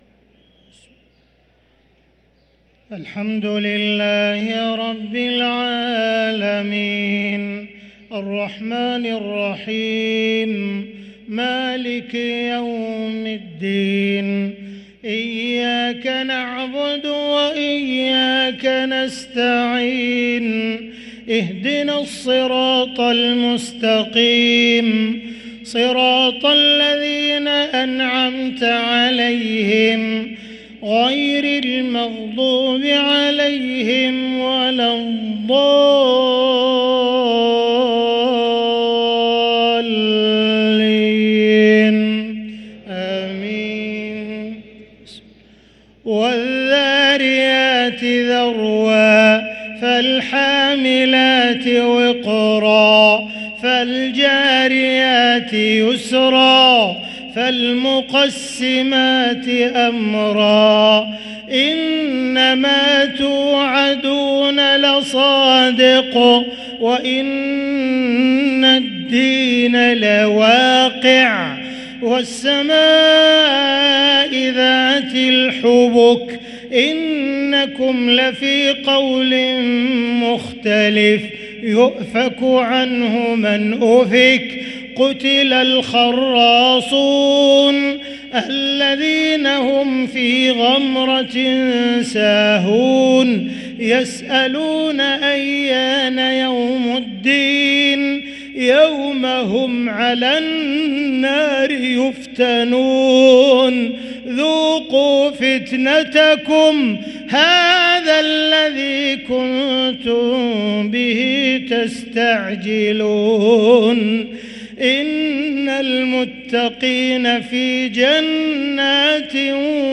صلاة العشاء للقارئ عبدالرحمن السديس 29 رجب 1444 هـ
تِلَاوَات الْحَرَمَيْن .